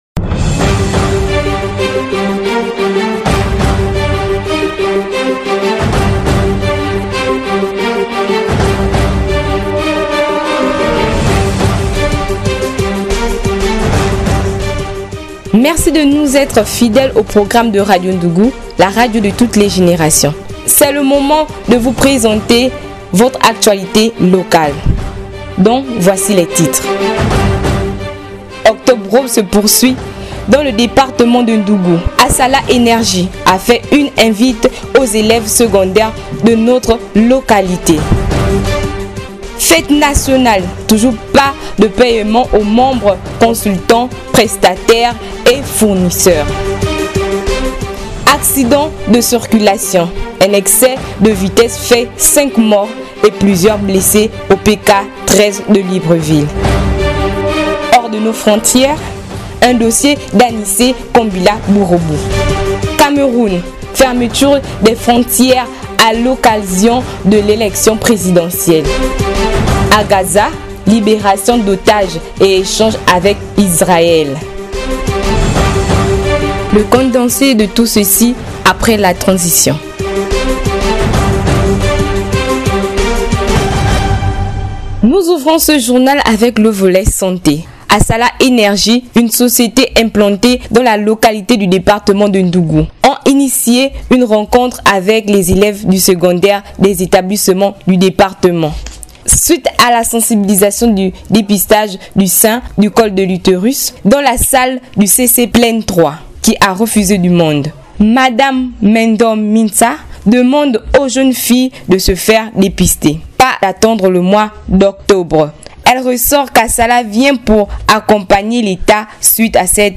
Nos journalistes et correspondants de terrain donnent la parole aux acteurs locaux : responsables associatifs, entrepreneurs, artistes, citoyens engagés… Ensemble, ils font vivre l’information de manière authentique, équilibrée et accessible à tous.